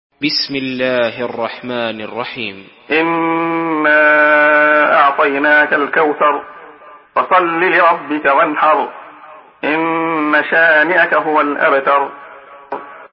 Sourate Al-Kawthar MP3 à la voix de Abdullah Khayyat par la narration Hafs
Une récitation touchante et belle des versets coraniques par la narration Hafs An Asim.